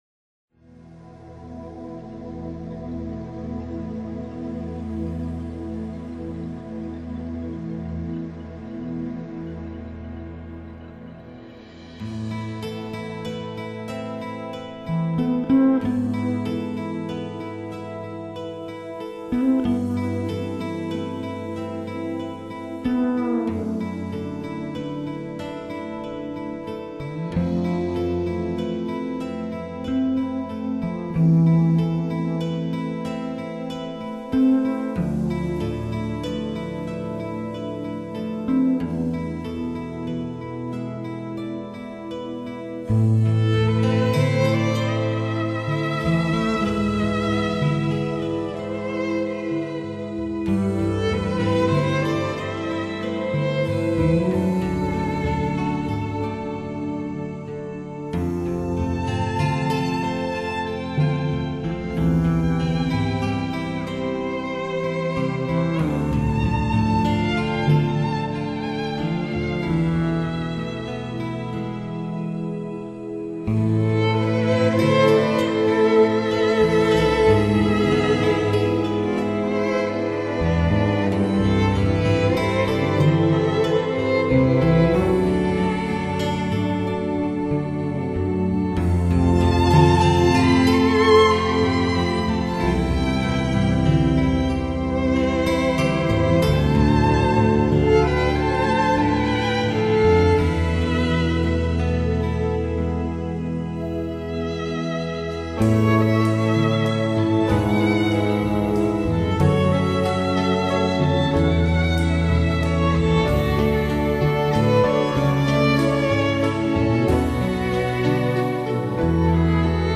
当小提琴声扬起，也牵曳你久未飞翔的想象力。
乐曲刚开始时候的氛围略带有些悲伤，
小提琴与其他弦乐器绵密而感性的将人们内在的 情绪牵引出来……